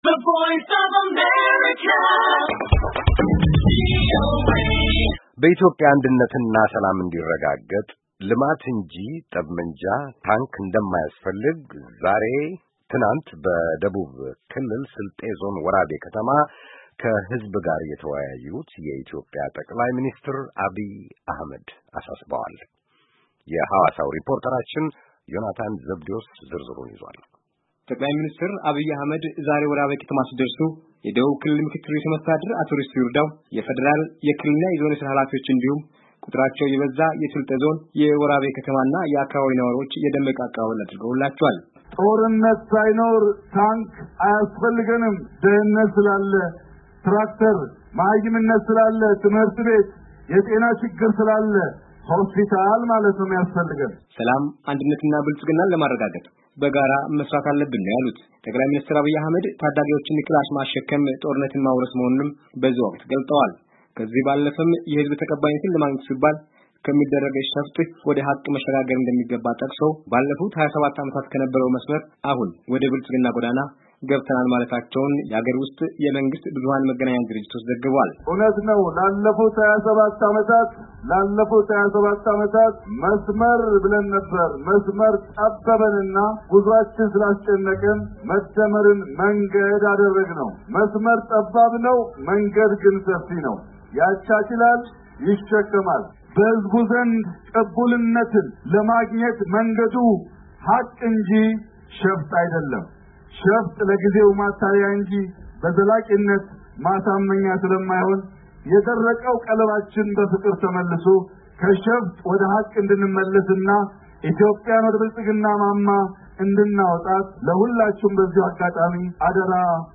በኢትዮጵያ አንድነትና ሰላም እንድረጋገጥ ልማት እንጂ ክላሽና ታንክ እንደማያስፈልግ ዛሬ በደቡብ ክልል ስልጤ ዞን ወራቤ ከተማ ከህዝብ ጋር የተወያዩት የኢትዮጵያ ጠቅላይ ሚኒስትር ዐቢይ አሕመድ ተናገሩ።